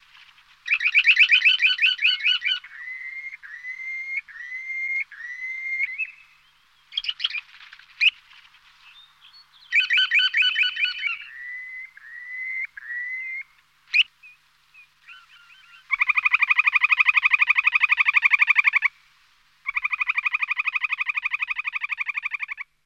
Woodcock sound - Eğitim Materyalleri - Slaytyerim Slaytlar
woodcock-sound